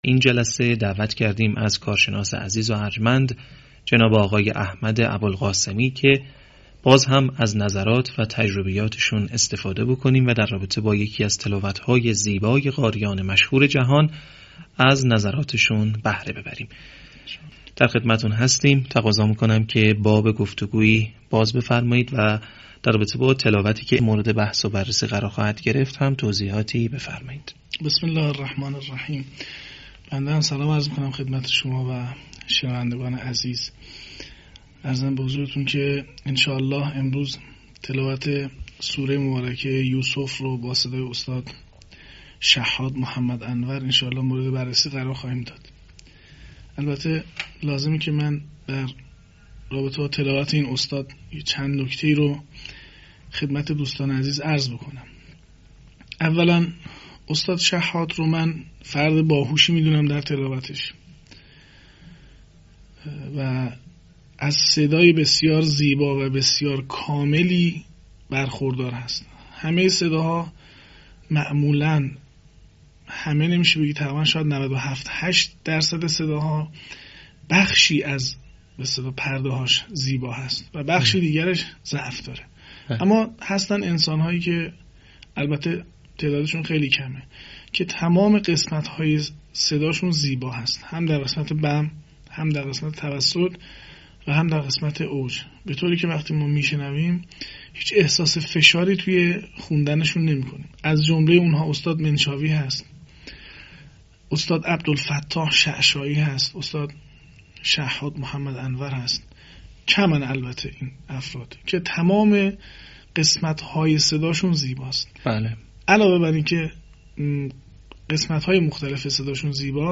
تحلیل تلاوت شحات محمد انور از سوره یوسف